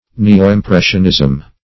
Neoimpressionism \Ne`o*im*pres"sion*ism\
(n[=e]`[-o]*[i^]m*pr[e^]sh"[u^]n*[i^]z'm), n. (Painting)